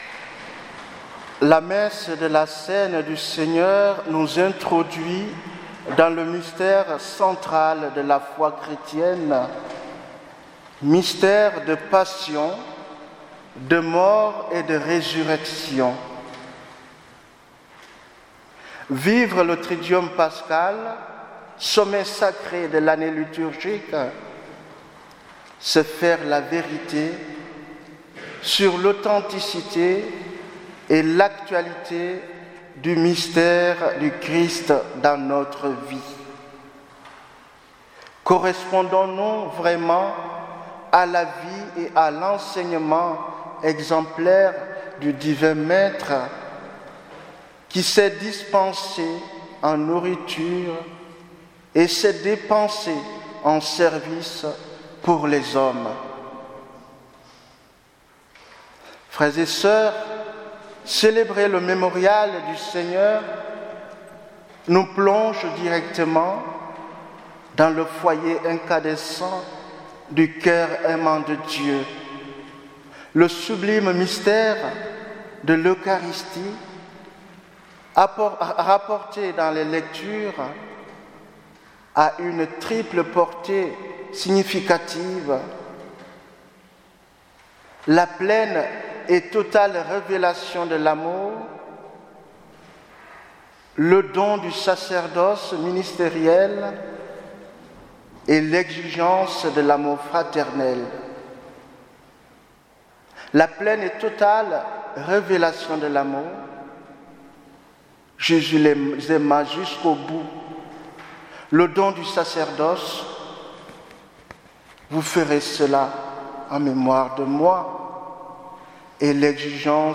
Homélie du Jeudi Saint 2018